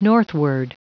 Prononciation du mot northward en anglais (fichier audio)
Prononciation du mot : northward